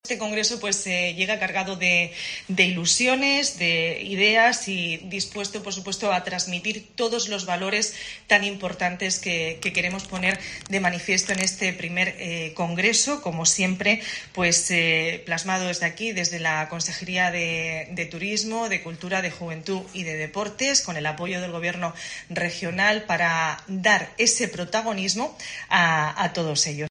Carmen María Conesa, consejera de Turismo, Cultura, Juventud y Deportes